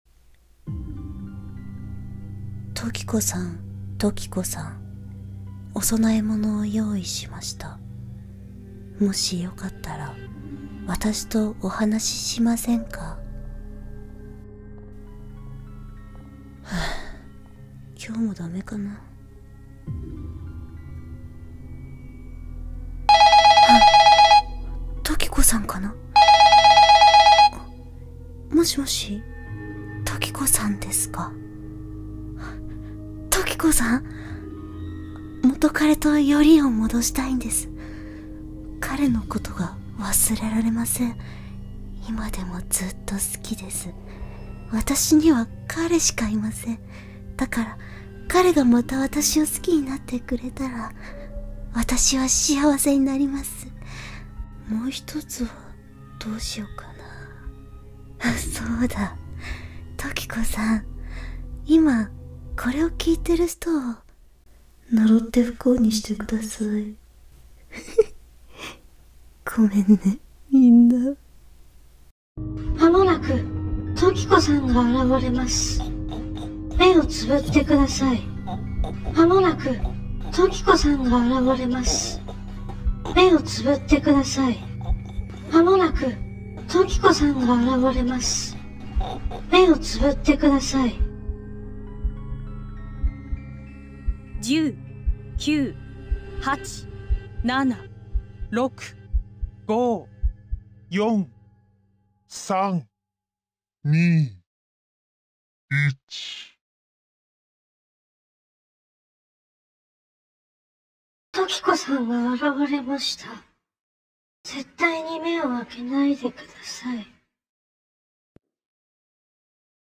【1人劇】ホラー